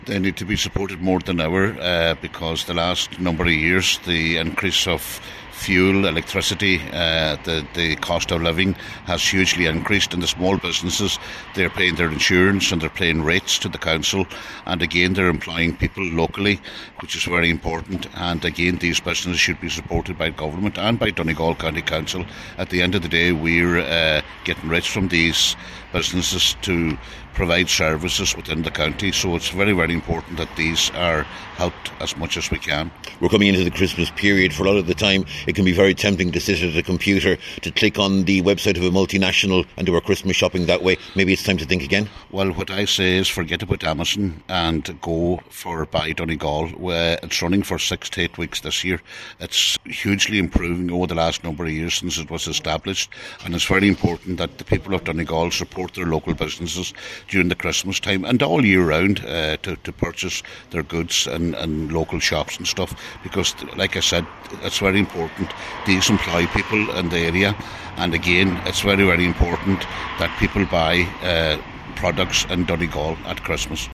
Glenties MD Cathaoirleach Cllr John Sheamais O’Fearraigh is urging people to support local businesses in the coming weeks, particularly as costs are going up……..